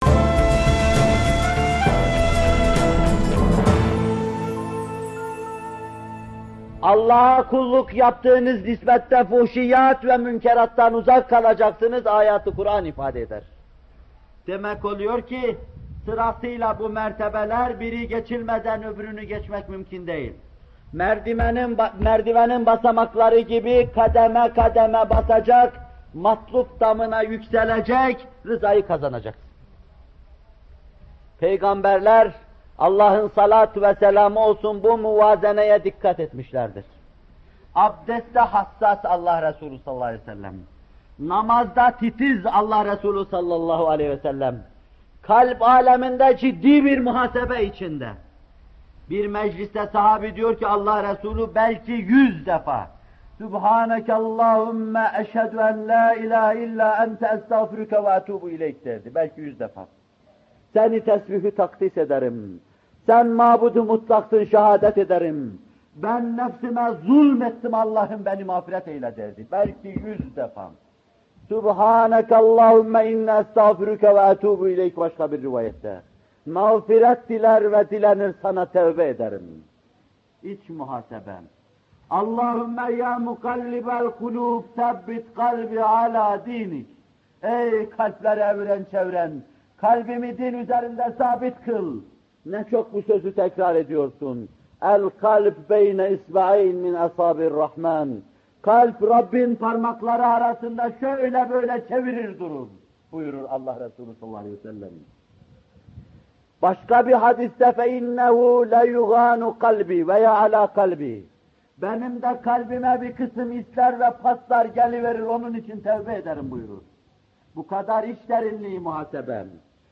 Bu bölüm Muhterem Fethullah Gülen Hocaefendi’nin 25 Ağustos 1978 tarihinde Bornova/İZMİR’de vermiş olduğu “Namaz Vaazları 2” isimli vaazından alınmıştır.